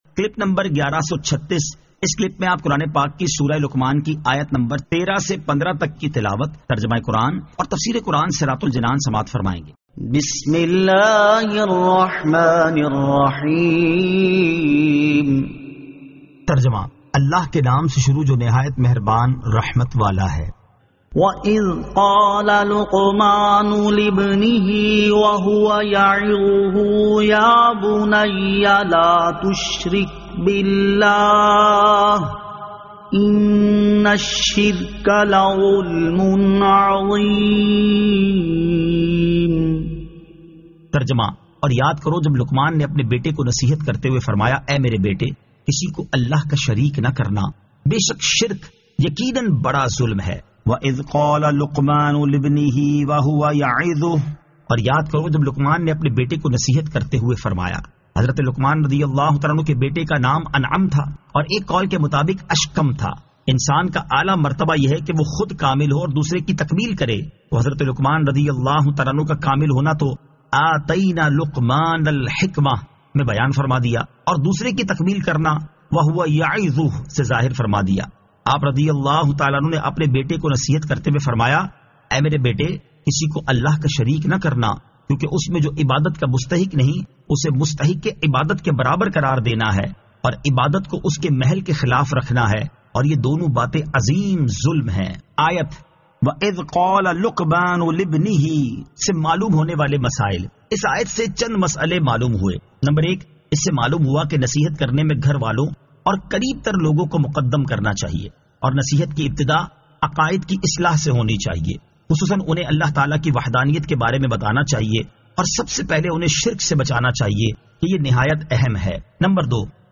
Surah Luqman 13 To 15 Tilawat , Tarjama , Tafseer